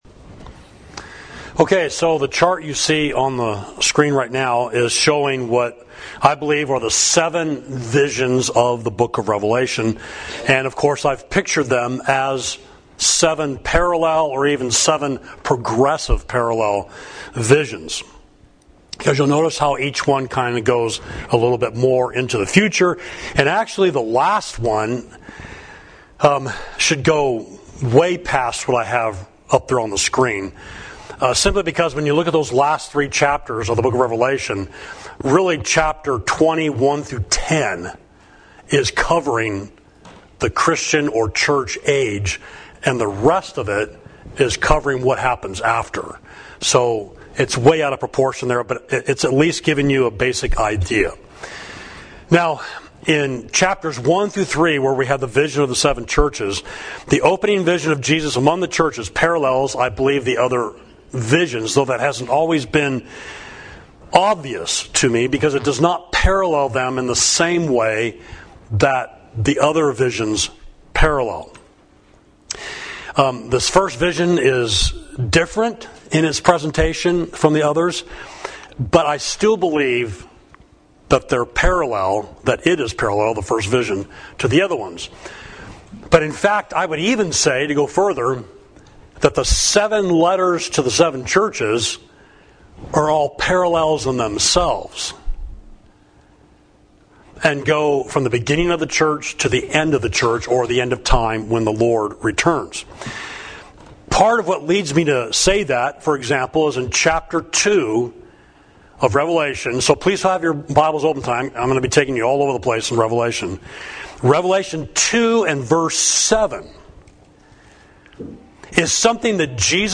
Sermon: The Parallel Visions of Revelation – Part 3